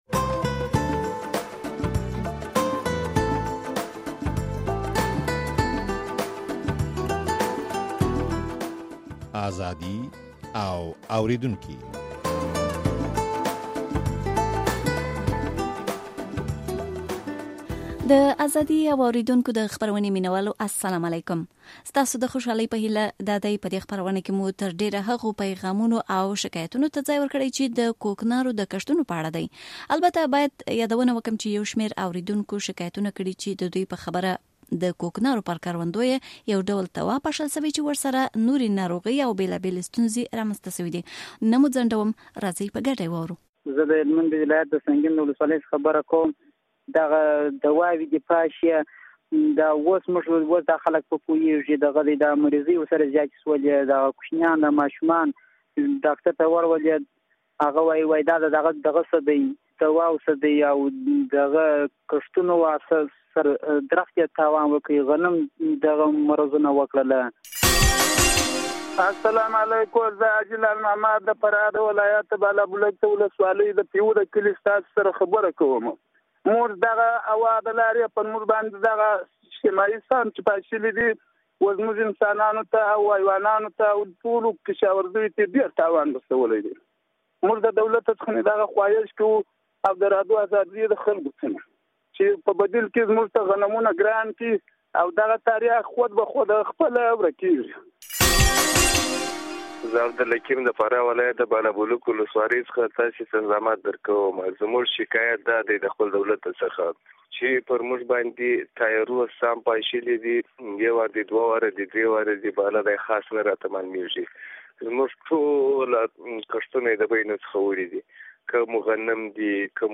د ازادي او اورېدونکو په دې پروګرام کې د اورېدونکو هغه ټليفوني پيغامونه اورئ چې ادعا کوي د کوکنارو پر کښتونو د دوا پاشلو دوى ته جلدي ناروغۍ پيدا کړې دي.